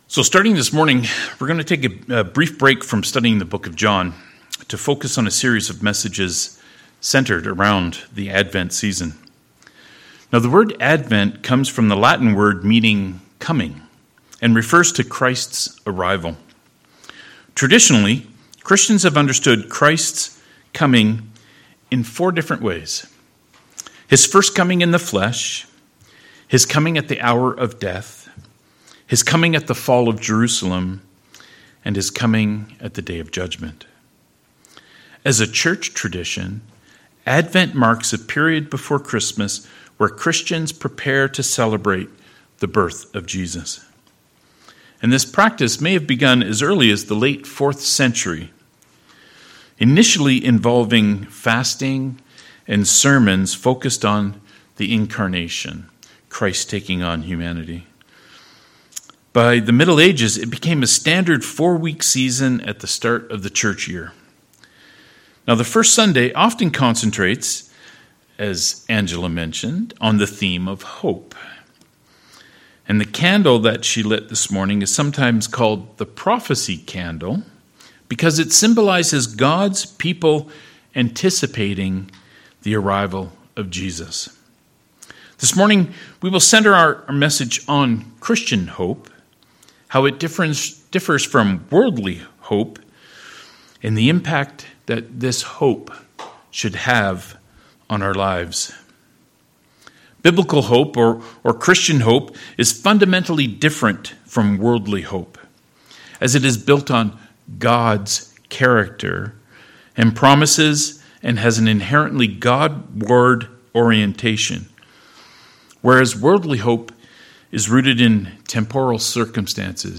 24-25 Service Type: Sermons « 3 Christians Virtues